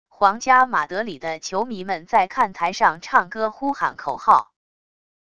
皇家马德里的球迷们在看台上唱歌呼喊口号wav音频